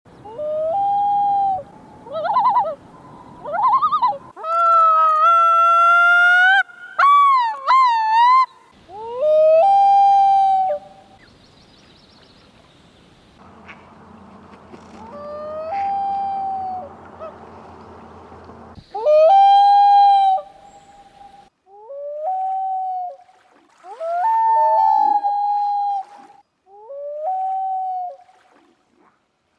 Listen to the amazing call sound effects free download
Listen to the amazing call of the "Common Loon", as it emits Strange Sounds that are soothing to the ears and bring relaxation and comfort, and see its stunning beauty, its beautiful marine environment, and also its picturesque nature.